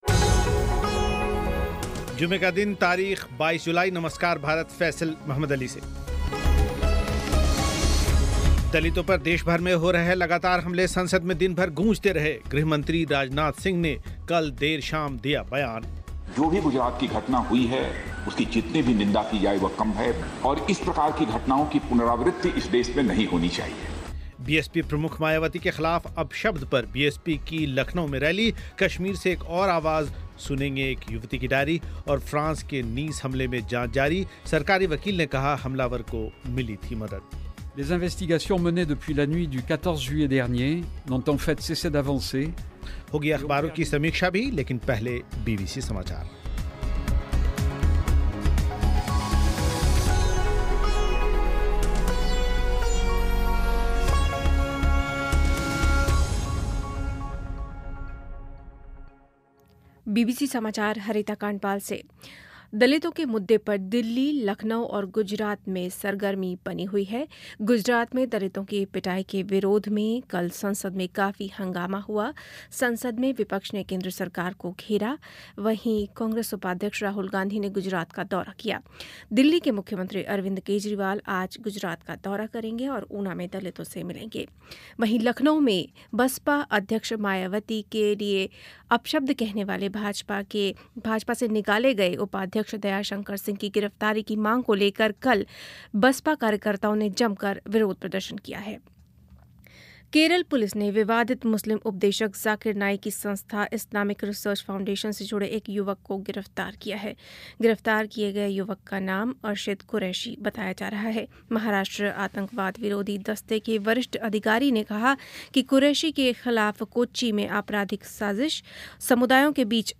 रिपोर्ट
एक साक्षात्कार